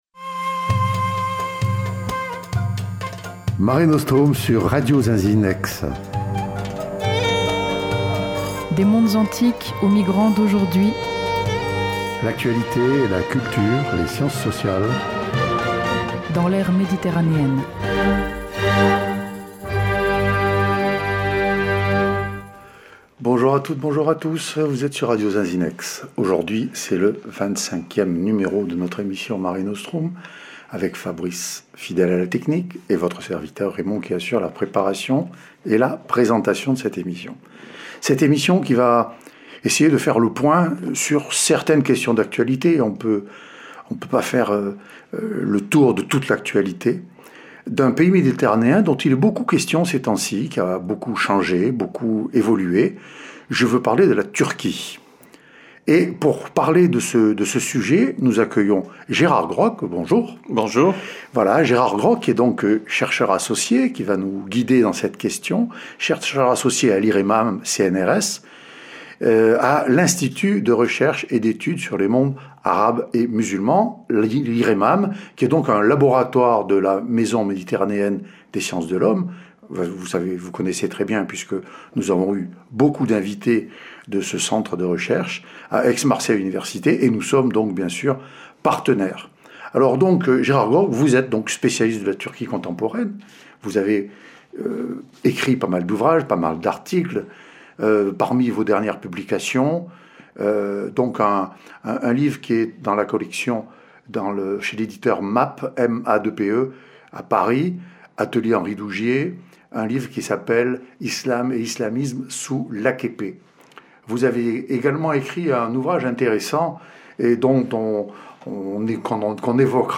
Mare Nostrum (Entretien radiophonique) : L'actualité de la Turquie : le renforcement des pouvoirs de l’Exécutif par Erdogan